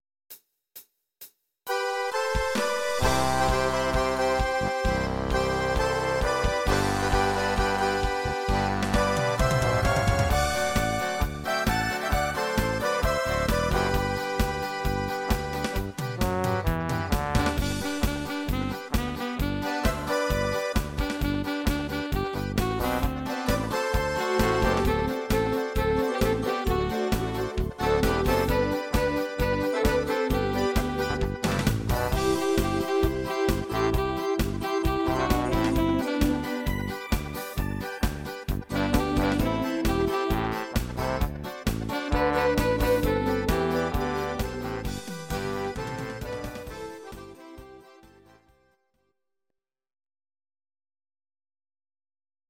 Audio Recordings based on Midi-files
Our Suggestions, Pop, German, 2010s